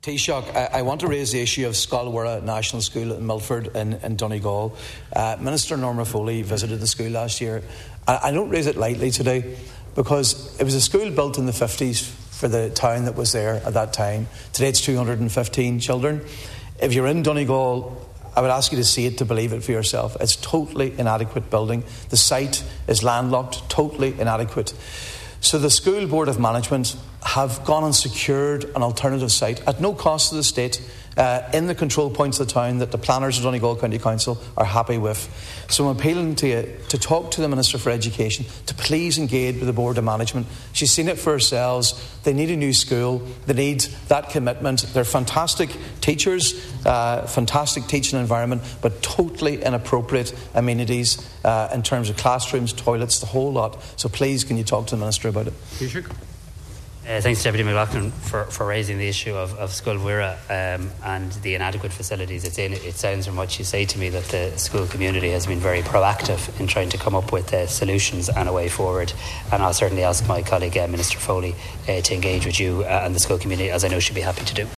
Deputy MacLochlainn told the Dáil that the current building is not up to par with the needs of the school which was built many decades ago: